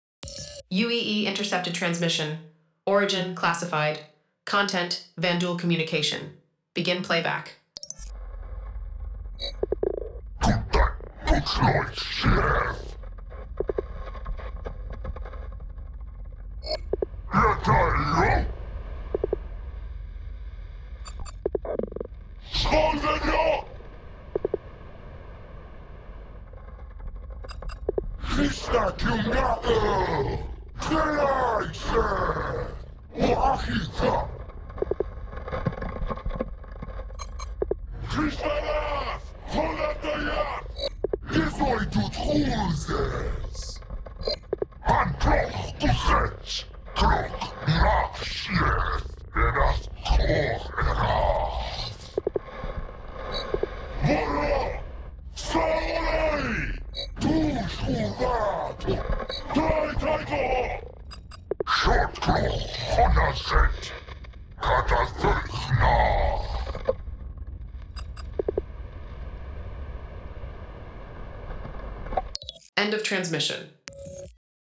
Der Marinegeheimdienst der UEE hat uns erlaubt, ehemals geheime Kommunikationssätze, die bei Begegnungen mit Vanduul abgefangen wurden, zu veröffentlichen. Ihre Untersuchung dieser Auszüge wird zu einem besseren Verständnis der vanduulischen Syntax und Diktion führen und könnte sogar sensible Daten liefern, die der Menschheit zum Sieg verhelfen. VanduulCommsIntercept.wav